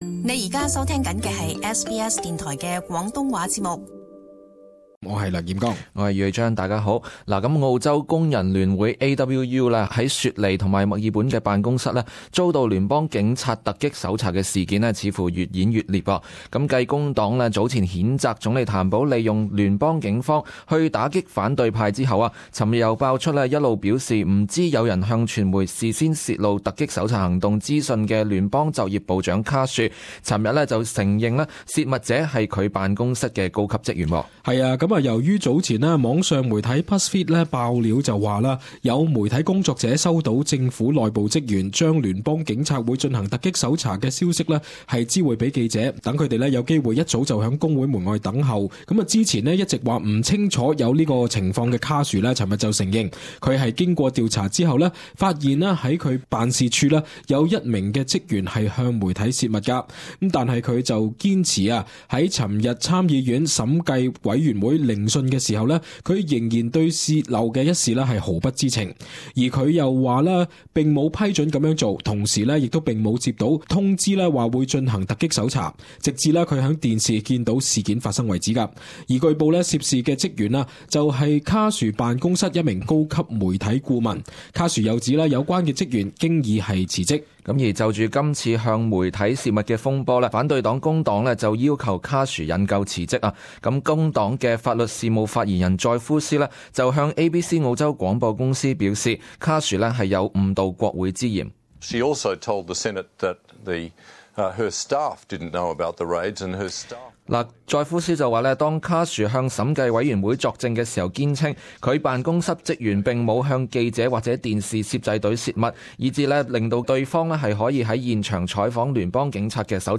【時事報導】警方查工會卡殊認職員向傳媒報料